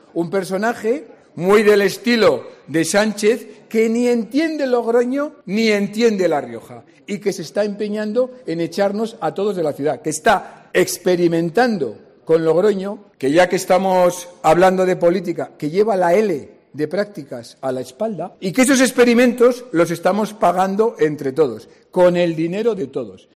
En los momentos previos a la cena también intervenía el candidato a la alcaldía de Logroño, Conrado Escobar.